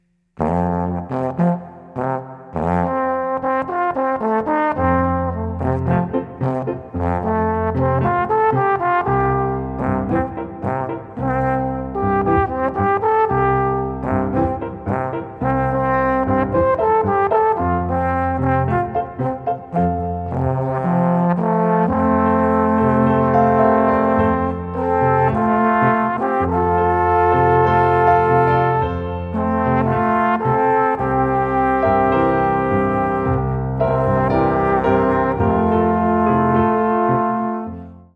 Alphorn in F, Klavier (optional)